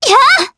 Frey-Vox_Attack2_jp_b.wav